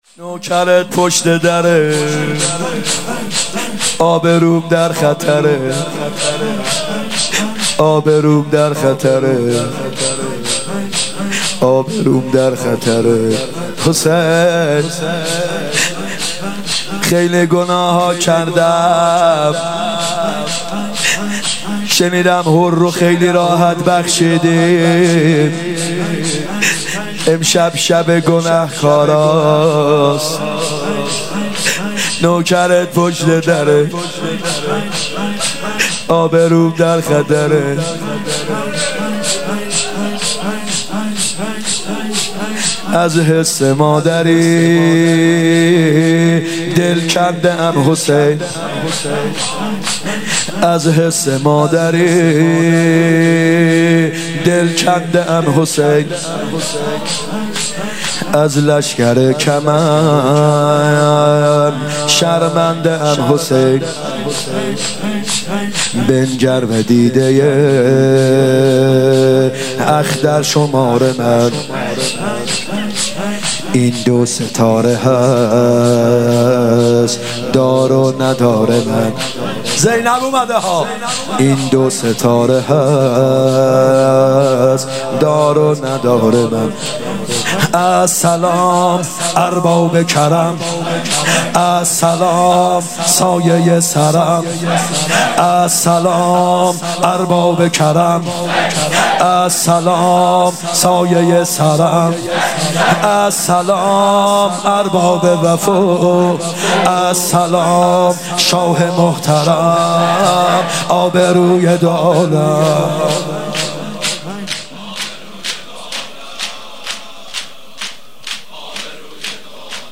محرم 96 شب چهارم شور ( نوکرت پشت دره ابروم در خطره)
هیات یامهدی عج(محرم 96)